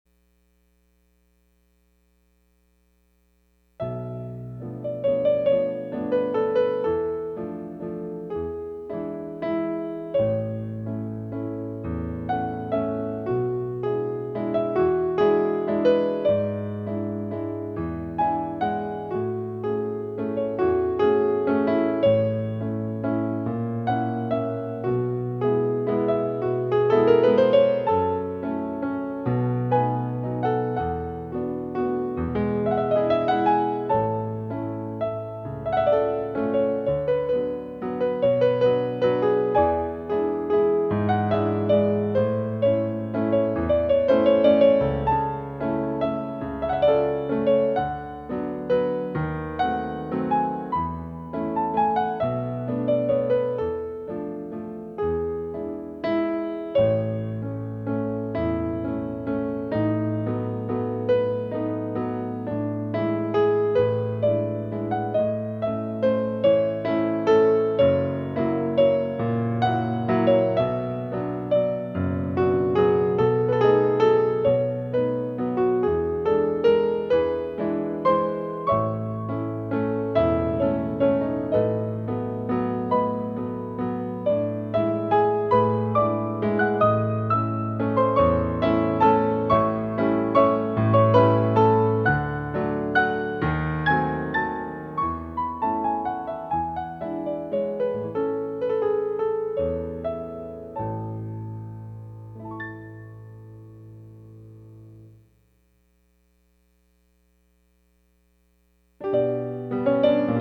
Music for Pirouettes